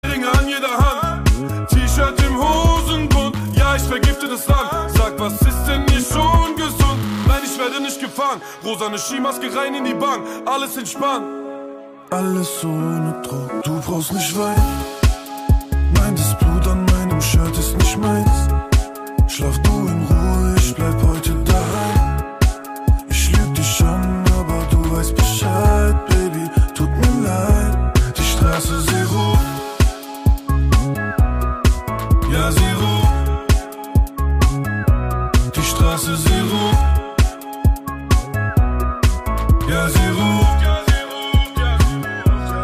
Kategorien: Rap/Hip Hop